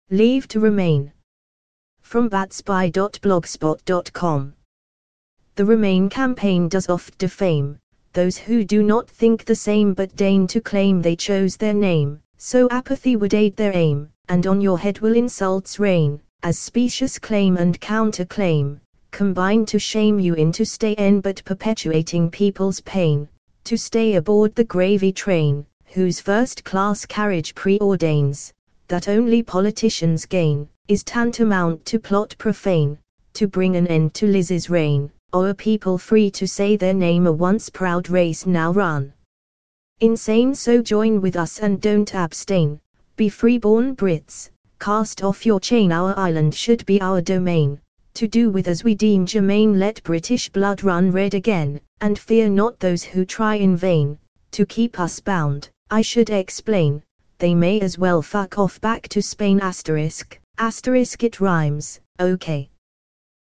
Leave to remain – TTS sounds hip.. or is it just me?! : TTS